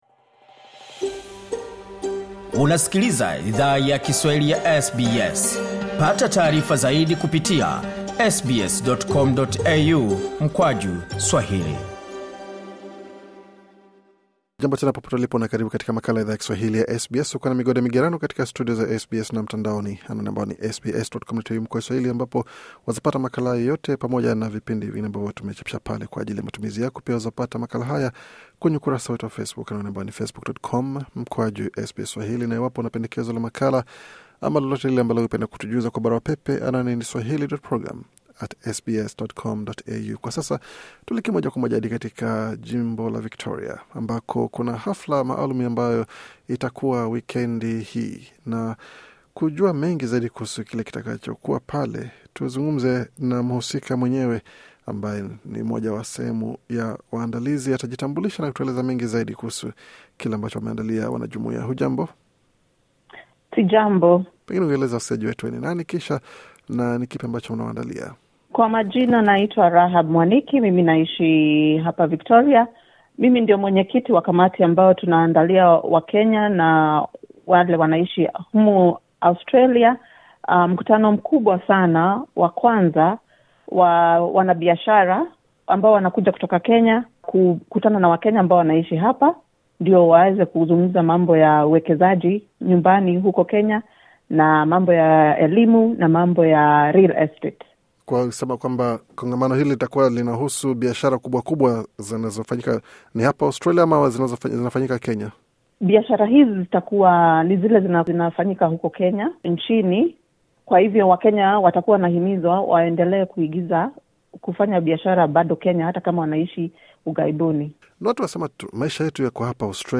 katika mahojiano maalum na SBS Swahili alifunguka kuhusu kazi ambayo kamati yake imefanya kuandaa kongamano hilo.